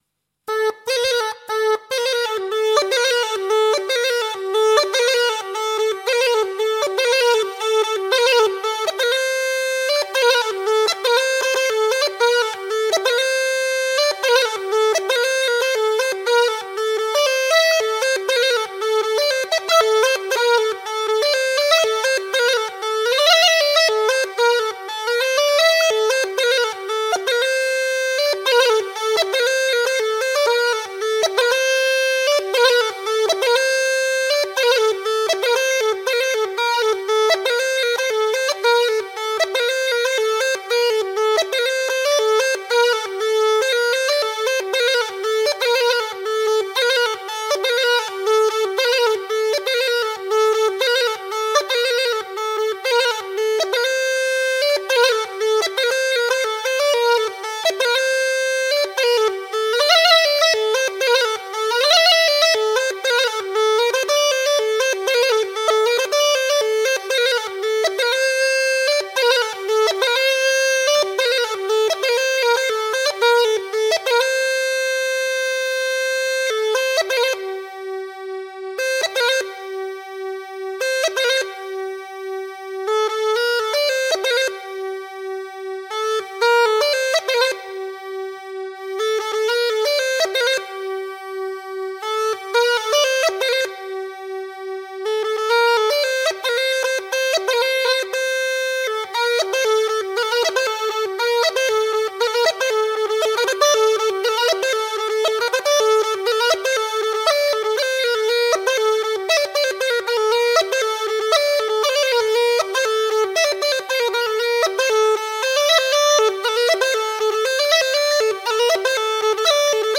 تمپو ۱۱۸ دانلود